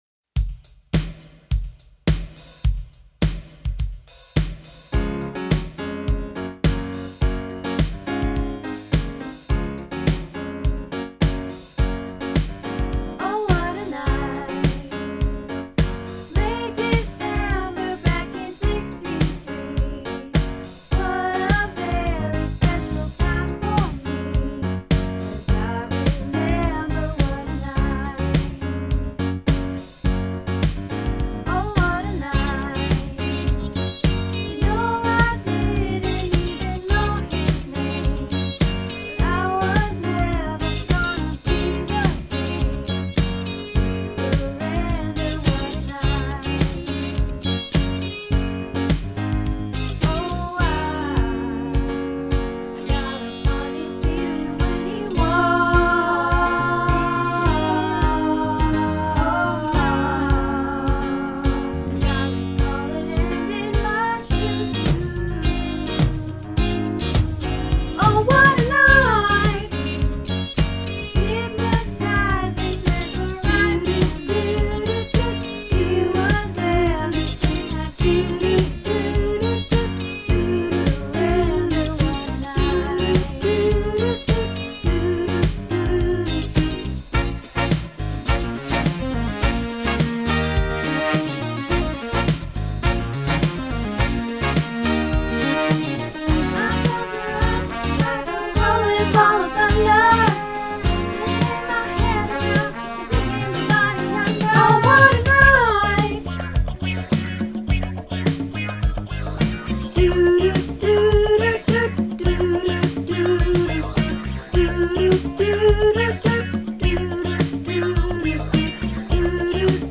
Oh What a Night - Tenor